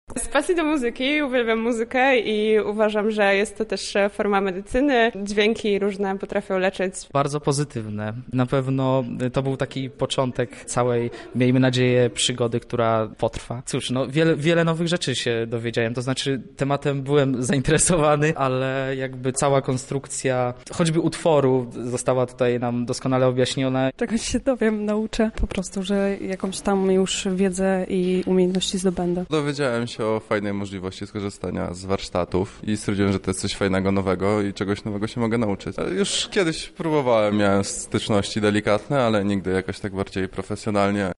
Tajniki powstawania bitów wraz z uczestnikami poznawała nasza reporterka